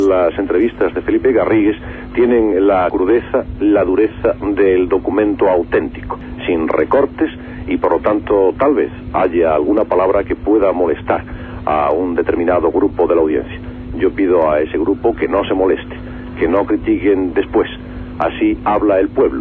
Presentació de les entrevsistes fetes en el poble.
Divulgació